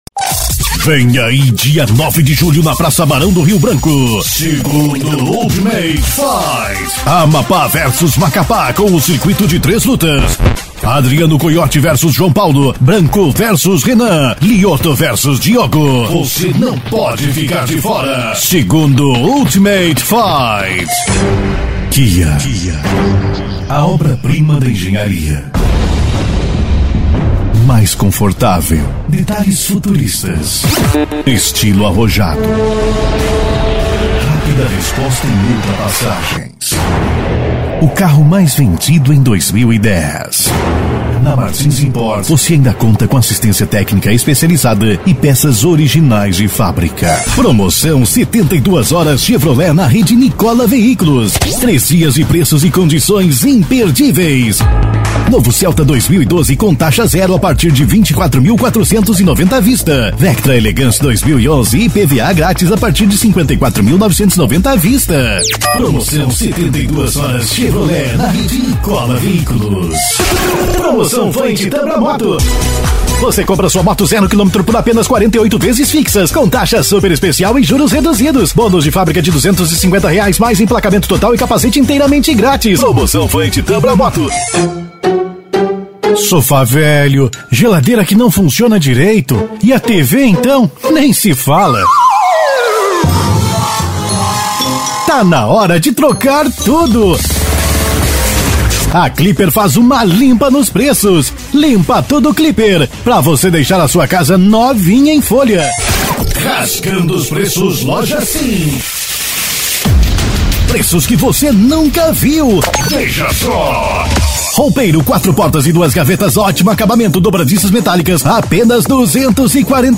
EstiloLocutores